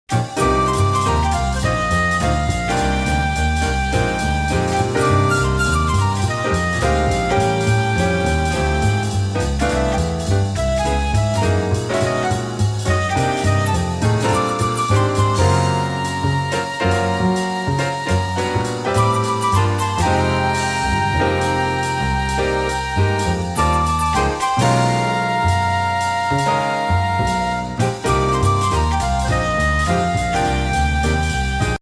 35 jazz originals